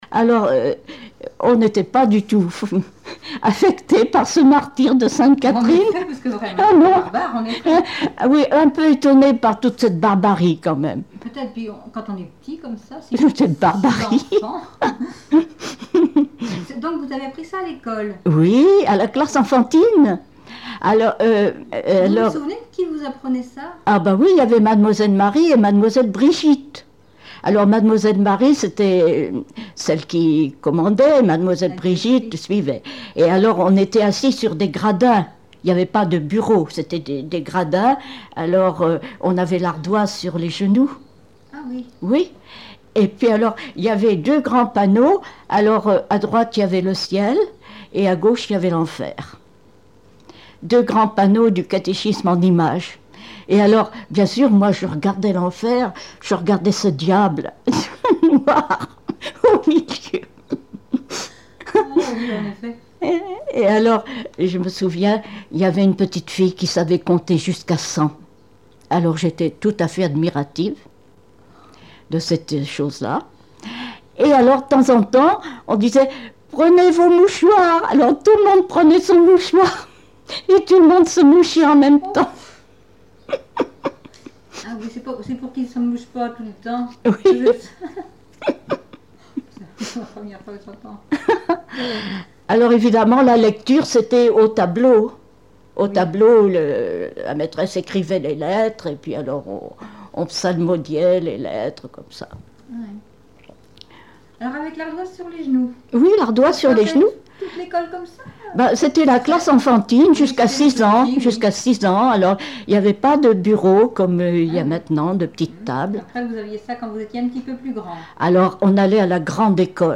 Chansons de cour d'école
Témoignages et chansons
Catégorie Témoignage